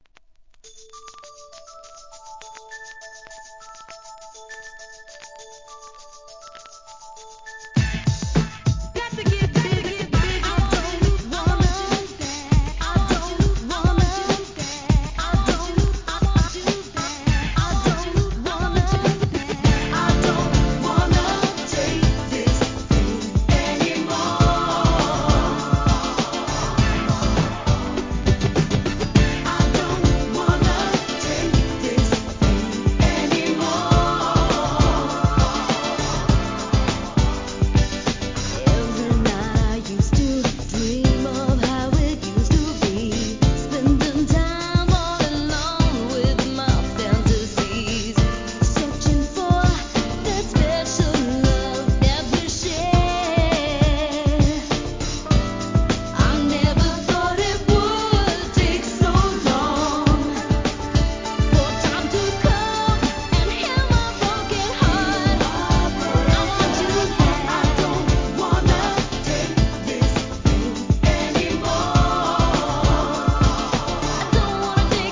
HIP HOP/R&B
グランドビート